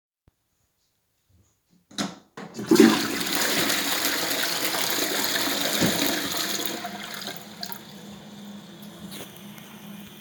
Ezeket a zajokat, zörejeket a fejlesztőházban vettem fel, amit mos közzé teszek.
1. WC lehúzás